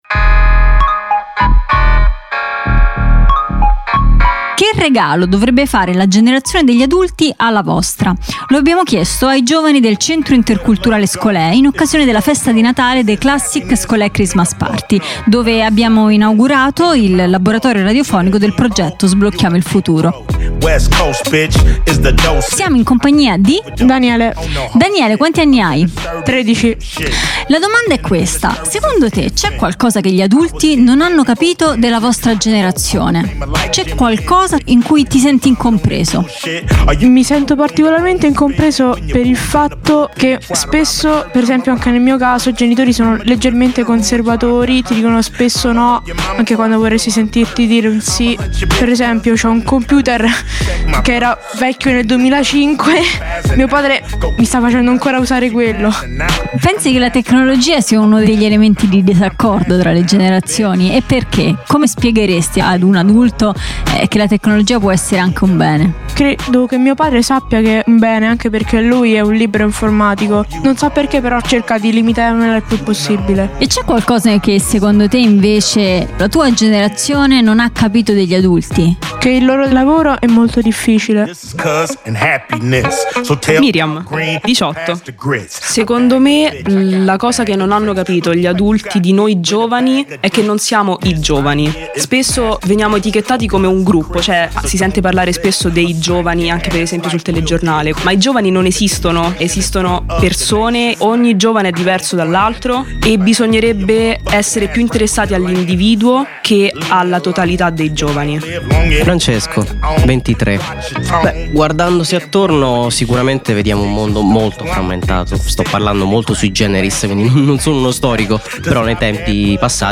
Il laboratorio radiofonico si presenta alla festa di Natale del Centro Interculturale Scholé